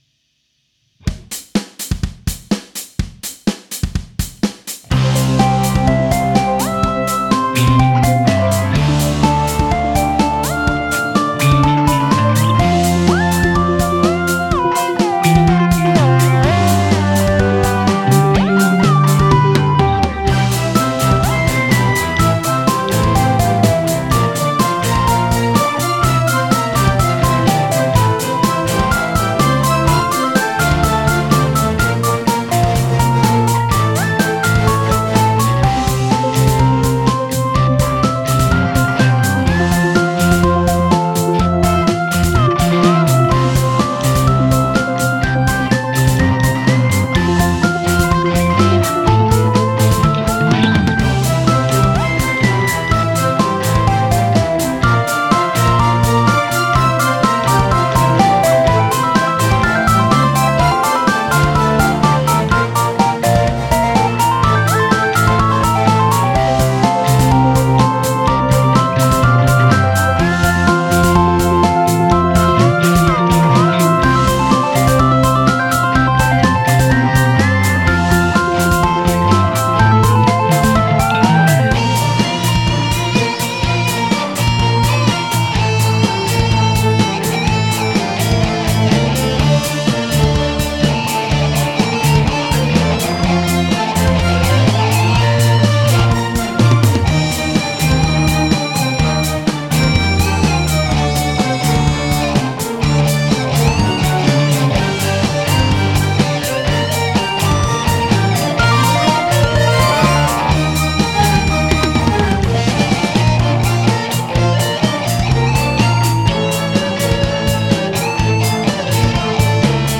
新しくエレキギターの弦を張り替えたので曲を作ってみました！
ギターとベースは以前ご紹介しました、ピックアップにネオジム磁石を着けた楽器です！